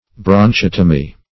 Search Result for " bronchotomy" : The Collaborative International Dictionary of English v.0.48: Bronchotomy \Bron*chot"o*my\, n. (Surg.)
bronchotomy.mp3